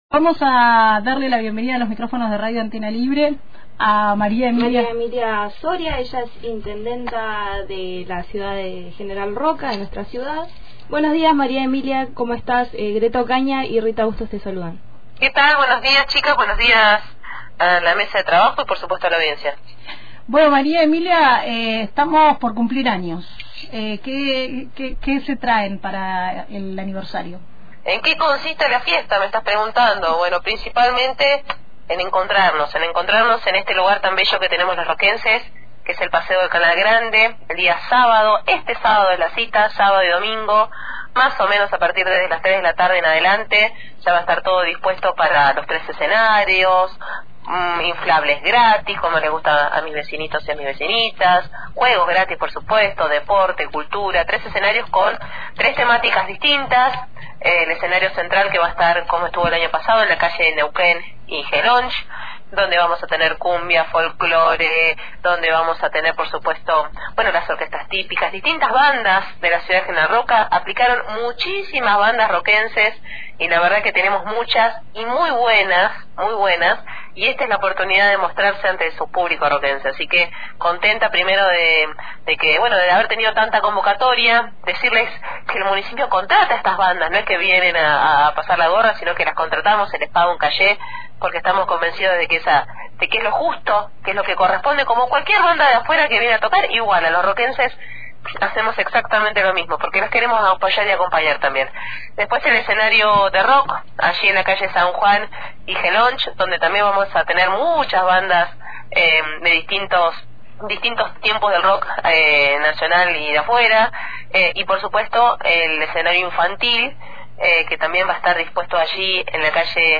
Hablamos con María Emilia Soria, intendenta de General Roca, acerca de los nuevos proyectos en General Roca tras la adhesión del Municipio al RIGI.